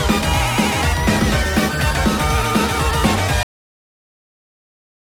I’ve been trying to find where this high frequency retro sound is from
here it’s a very high frequency sound
it’s in the background